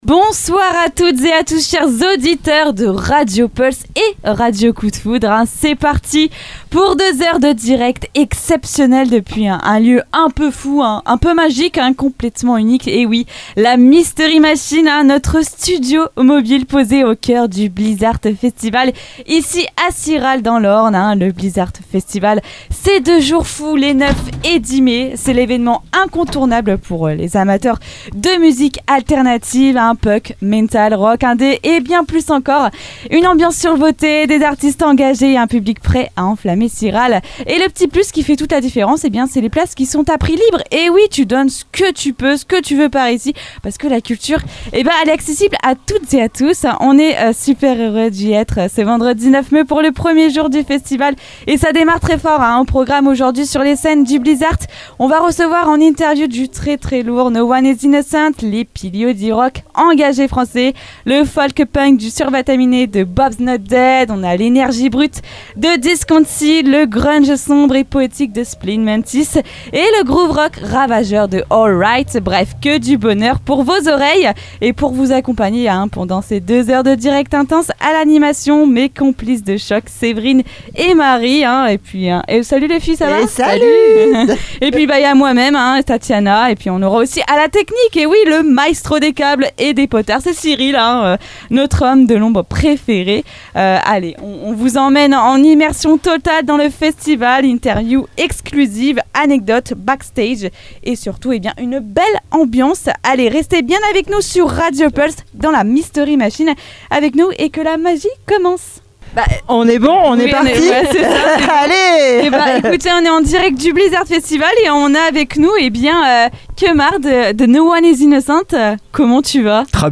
À cette occasion, nous avons eu le privilège d’interviewer Kemar, figure emblématique du rock français, en direct depuis la Mystery Machine (studio radio aménagé dans une camionnette) de Radio Pulse, avec une diffusion en simultané sur Pulse et Radio Coup de Foudre. Dans cet échange, Kemar revient sur plus de trente ans de carrière, ses combats, et l’histoire qui entoure la sortie du best-of Colères, enrichi de nouveaux titres percutants comme L’Arrière-Boutique du Mal et Ils Marchent. Fidèle à l’ADN du groupe, il aborde sans détour les dérives du pouvoir, la manipulation numérique et les violences invisibles, tout en livrant une parole sincère, teintée de lucidité.